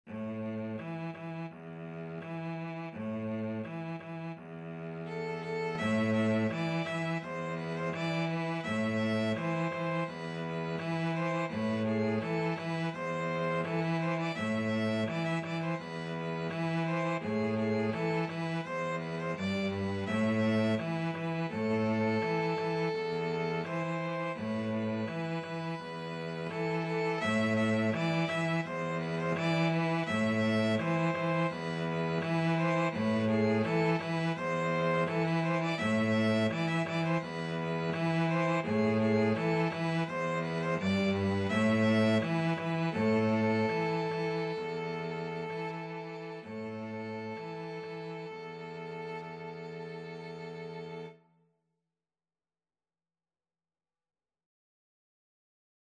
Christmas Christmas Violin-Cello Duet Sheet Music Pat-a-Pan
Free Sheet music for Violin-Cello Duet
Steady two in a bar ( = c. 84)
A minor (Sounding Pitch) (View more A minor Music for Violin-Cello Duet )
2/2 (View more 2/2 Music)
Traditional (View more Traditional Violin-Cello Duet Music)